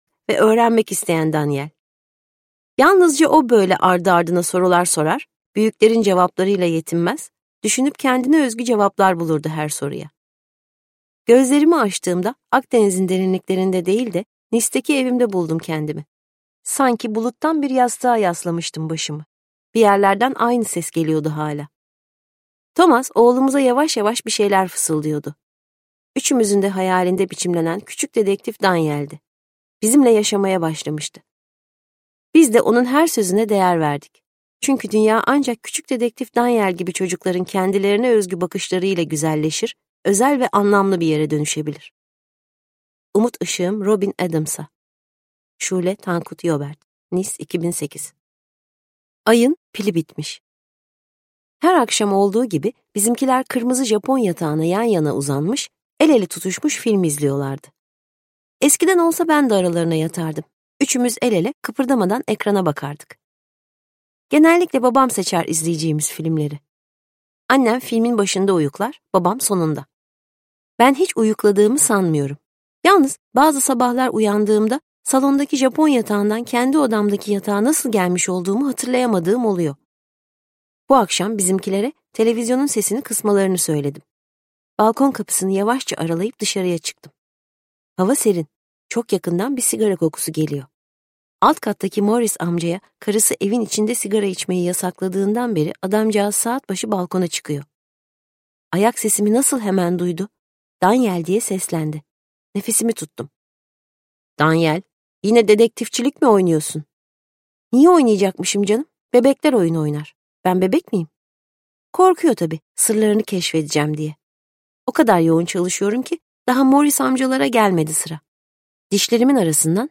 Seslendiren